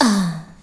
VOX SHORTS-1 0011.wav